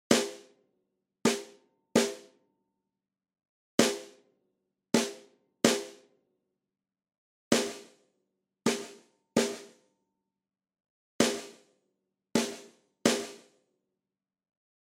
Вылез очень неприятный шершавый потрескивающий звук.